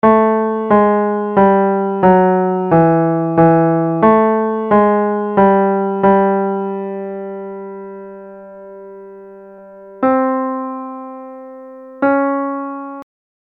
Key written in: D Major
Each recording below is single part only.
a piano